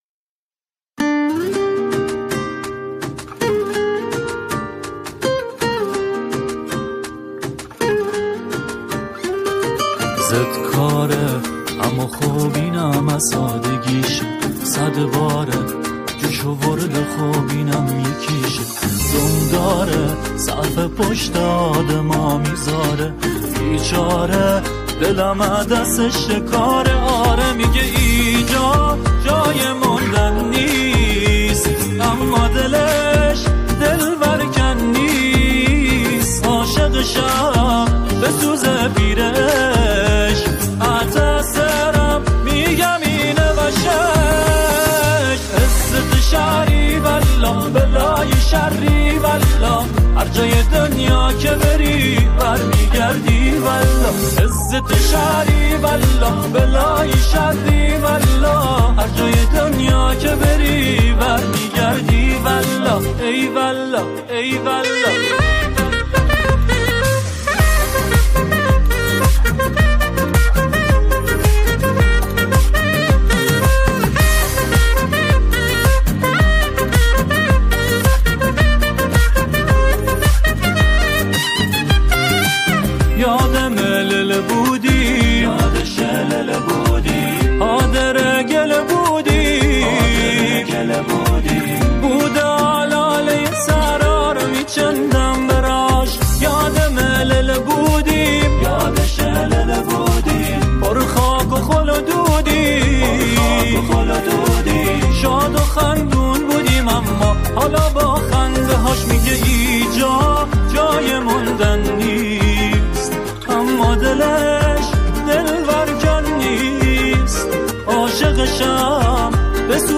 گروه همخوان